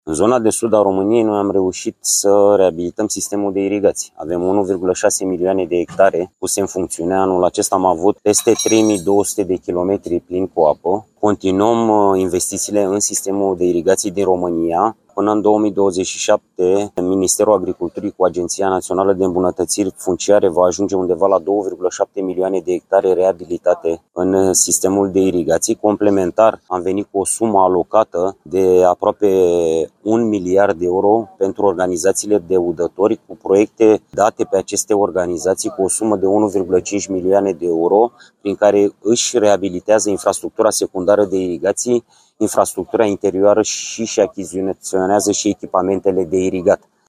Ministrul Florin Barbu a declarat, la Arad, că, în următorii doi ani, suprafața terenurilor irigate va crește semnificativ.